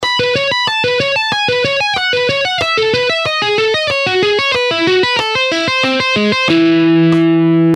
By combining the use of the pick and fingers, hybrid picking enables players to achieve a rich and dynamic sound that can elevate their playing to new heights.
Hybrid-Picking-Exercise-3.mp3